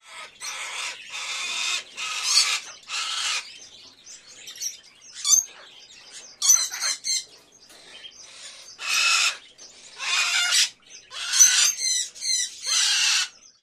Birds In Pet Shop